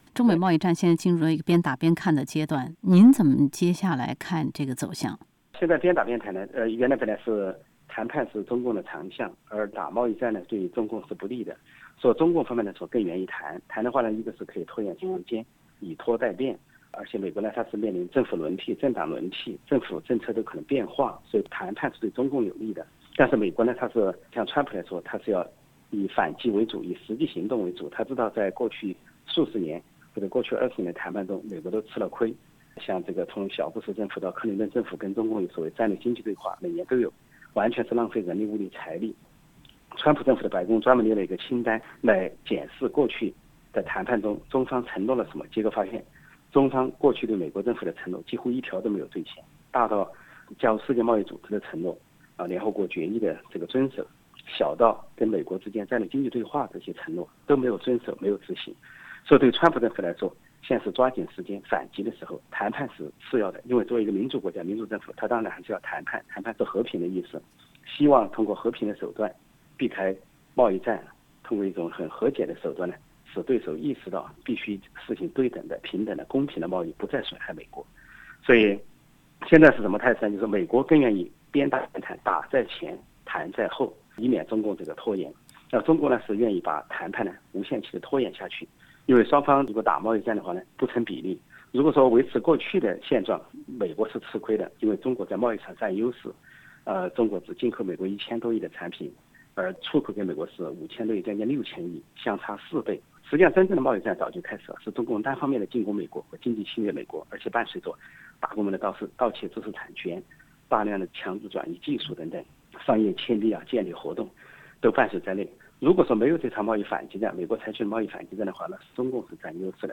【中美贸易战】陈破空专访：“一带一路”还是“国际包养”？